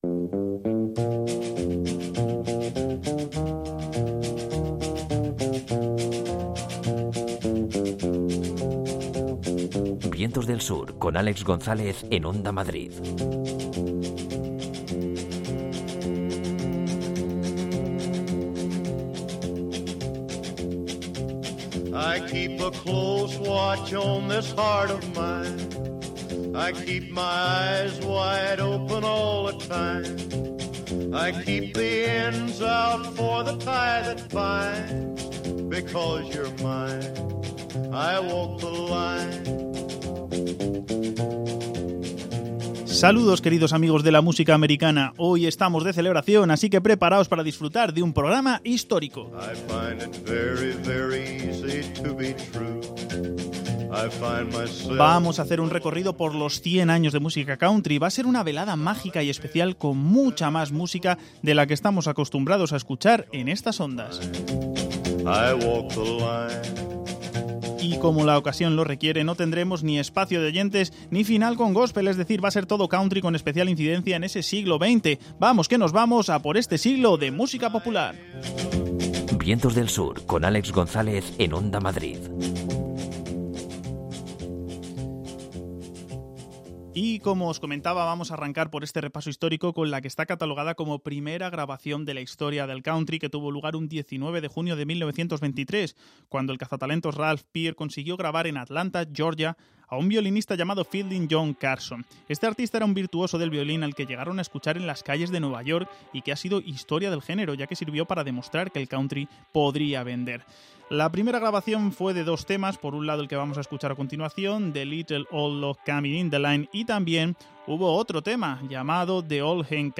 VDS 3X38: Un siglo de country